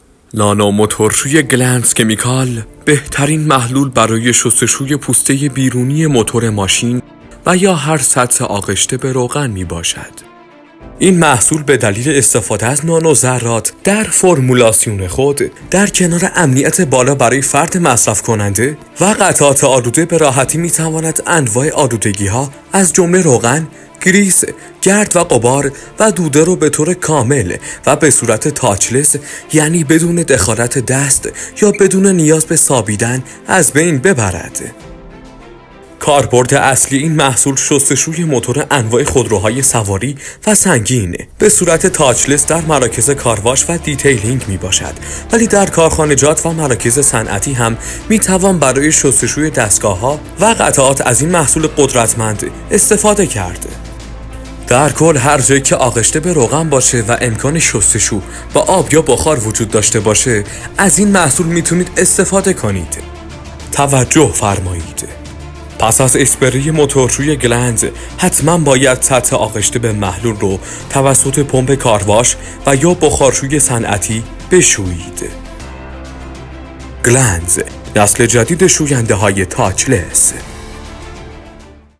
ویس اول : [ تبلیغاتی: خصوصیات محصول ]